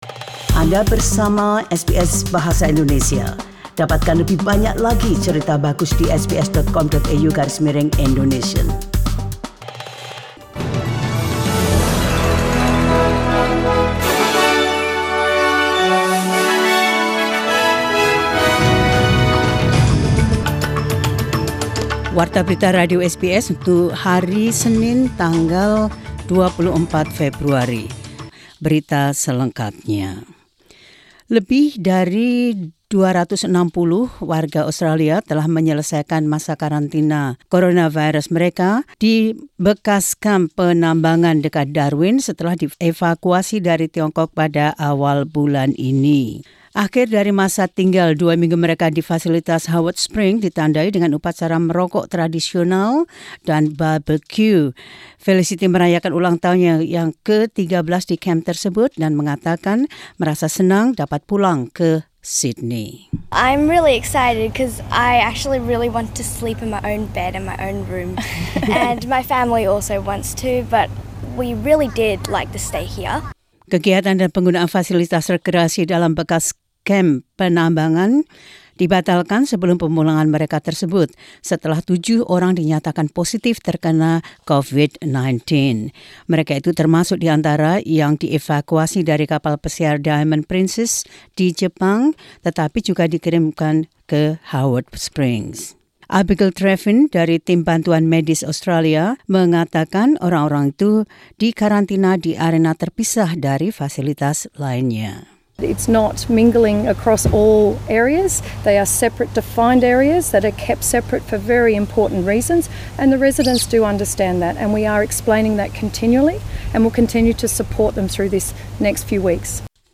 SBS Radio News in Indonesian 24 Feb 2020.